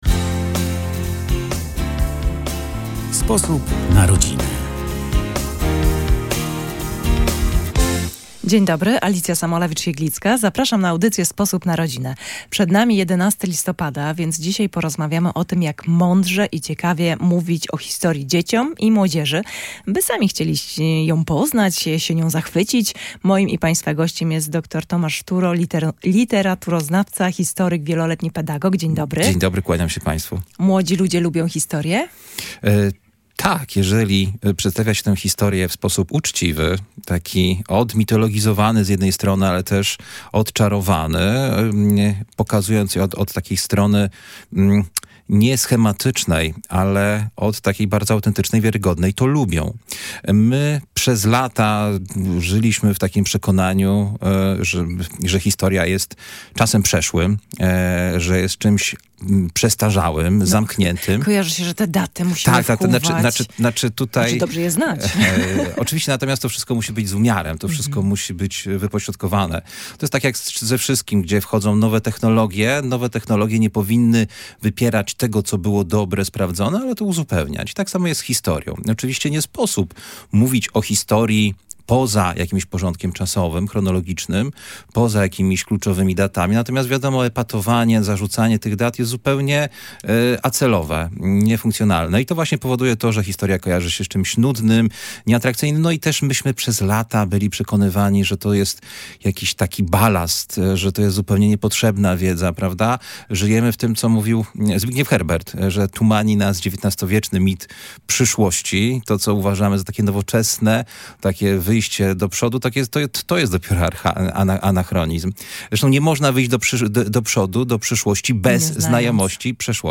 Jak opowiadać dzieciom i młodzieży o historii? Rozmowa z historykiem i wieloletnim pedagogiem